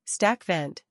stak - vent